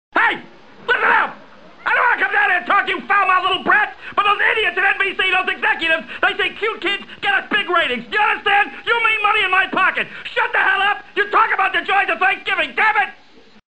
Tags: Holiday Thanksgiving TV Sounds Thanksgiving TV Episodes Thanksgiving Day Television